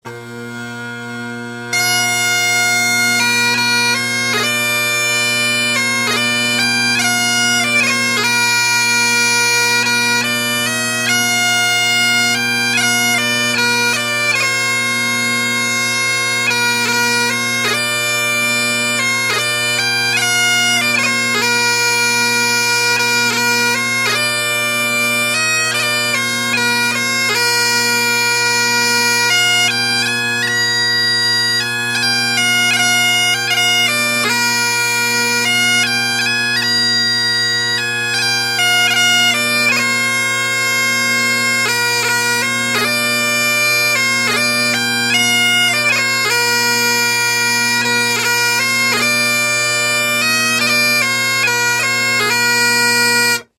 Bagpipe Music Samples
Tunes for Funerals